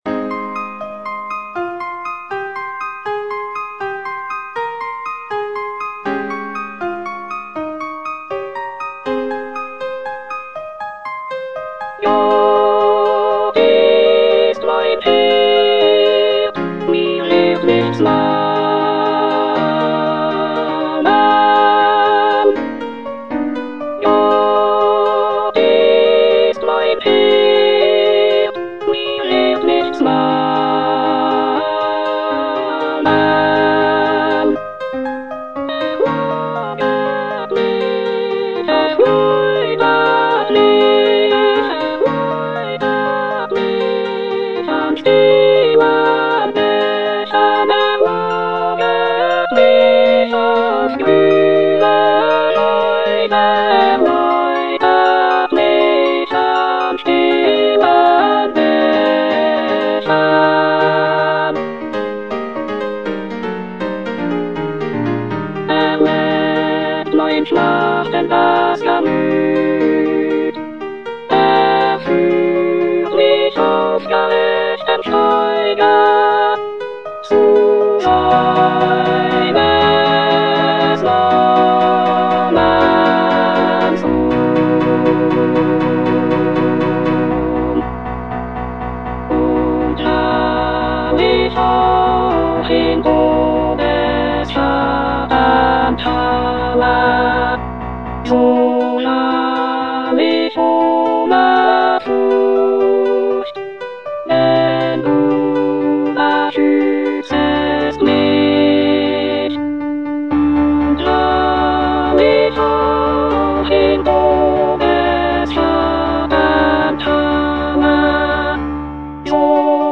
F. SCHUBERT - PSALM 23 D706 (Ed. 2) Alto I (Emphasised voice and other voices) Ads stop: auto-stop
"Psalm 23 D706 (Edition 2)" is a choral composition by the renowned Austrian composer Franz Schubert.
Schubert's setting of this psalm is characterized by its serene and lyrical melodies, evoking a sense of peace and tranquility. The composition features rich harmonies and expressive vocal lines, showcasing Schubert's mastery of choral writing.